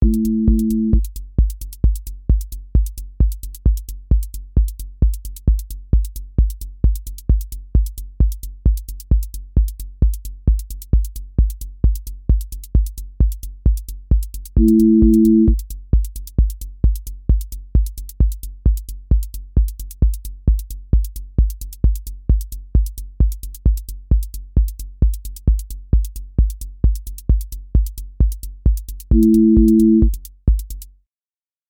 QA Listening Test techno Template: techno_hypnosis
• voice_kick_808
• voice_hat_rimshot
• voice_sub_pulse
• tone_brittle_edge
• motion_drift_slow
Techno pressure with driven motion